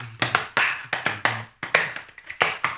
HAMBONE 04-R.wav